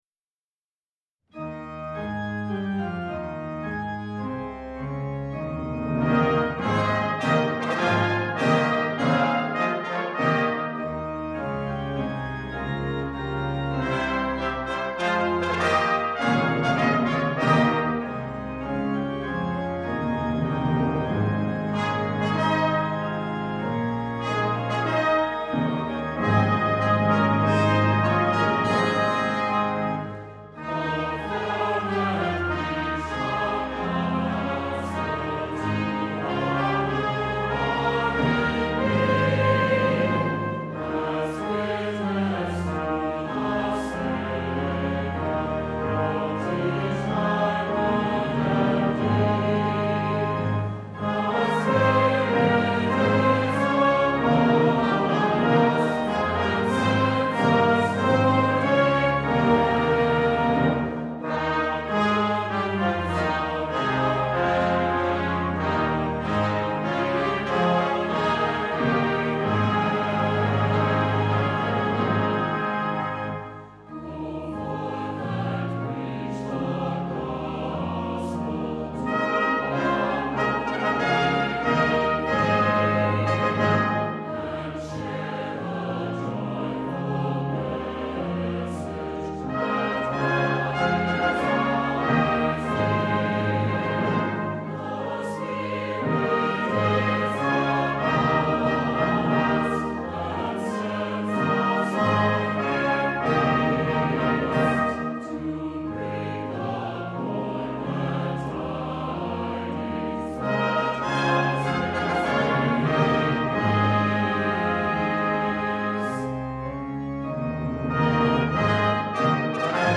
Voicing: Congregation, Optional SATB